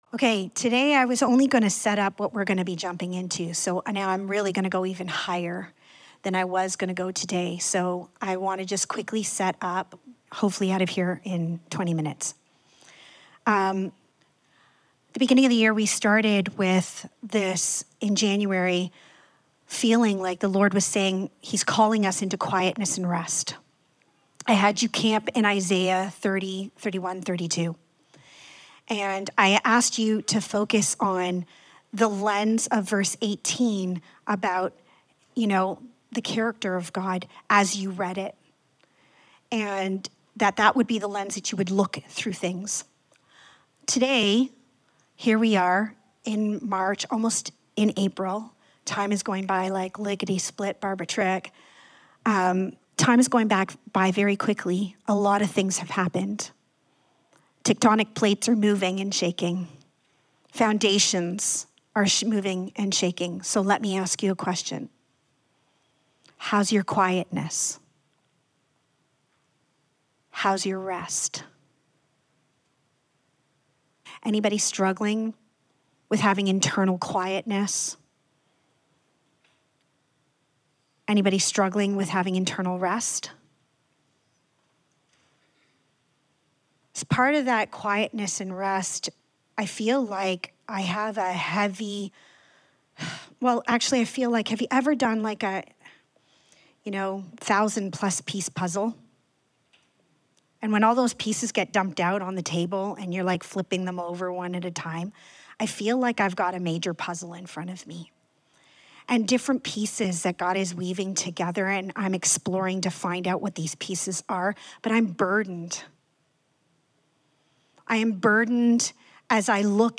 Out of the Shadows Service Type: Sunday Morning We are opening the door to a brand-new message series